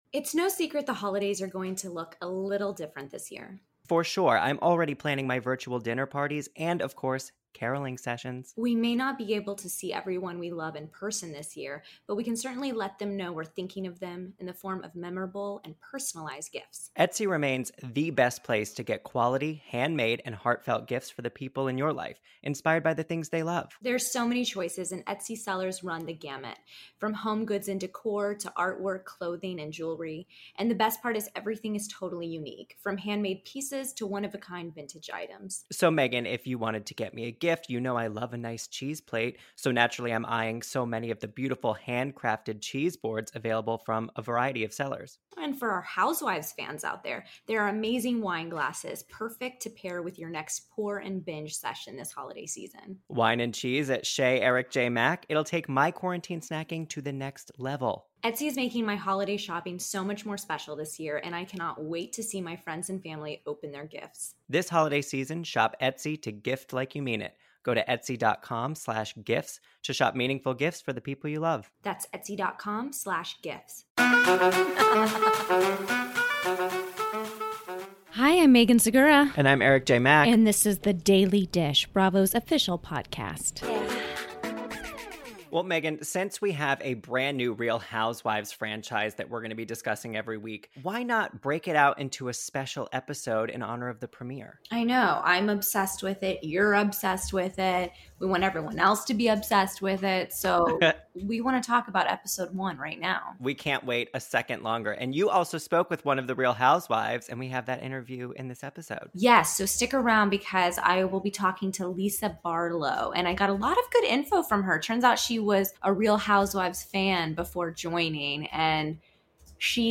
Plus, Lisa Barlow herself calls in to share how RHOSLC came to be, reveal which Real Housewife career she would love to emulate, and more.